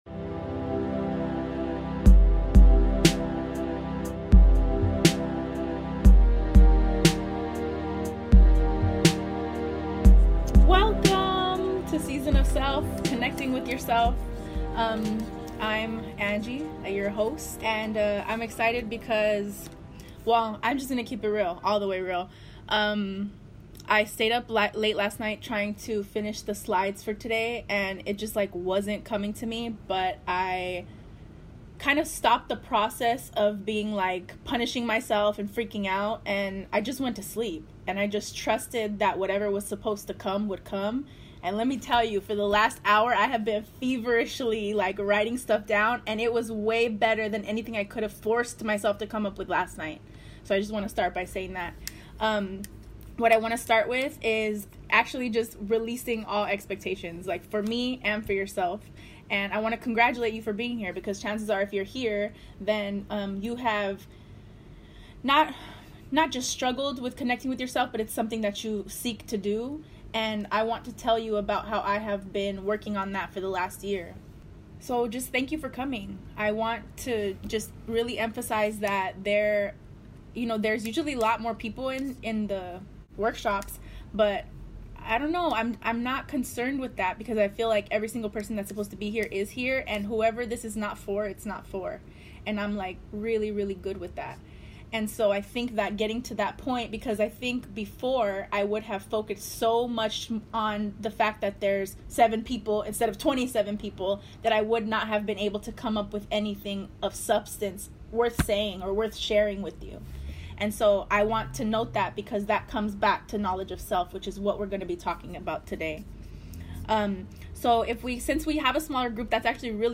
This episode is the audio recording of a zoom workshop I did in 2020.